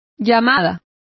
Complete with pronunciation of the translation of calls.